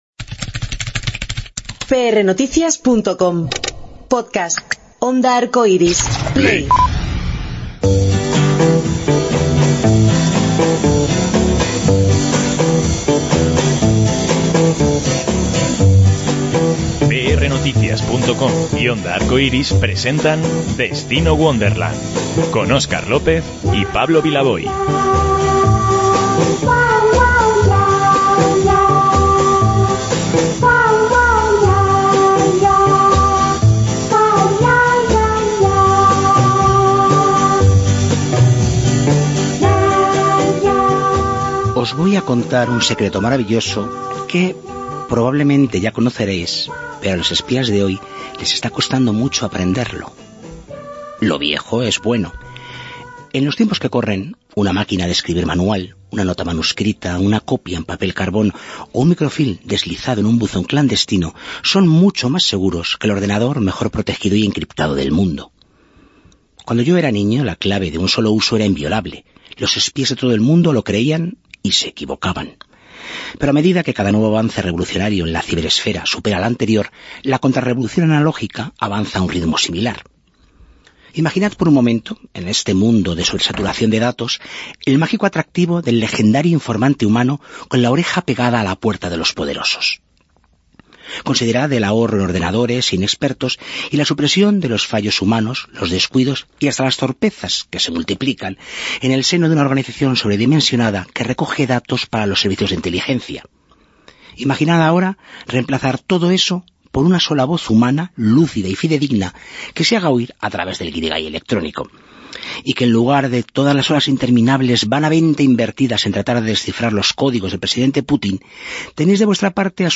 Conocemos más de este proyecto, y de él, en esta entrevista.